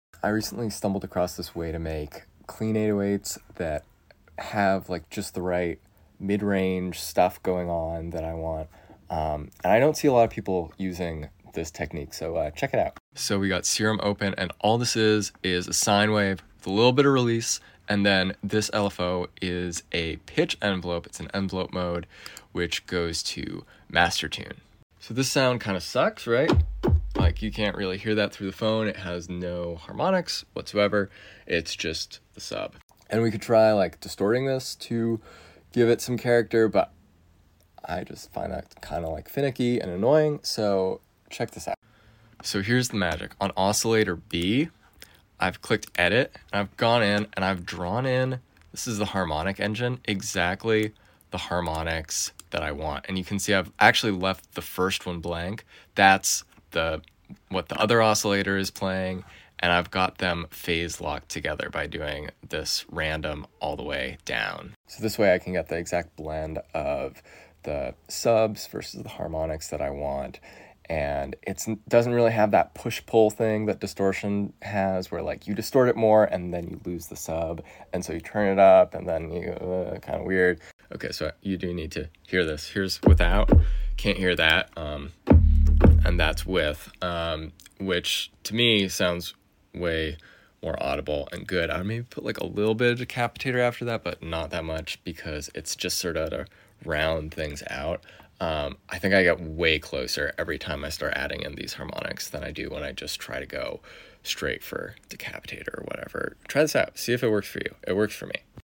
Better 808s in serum #808 sound effects free download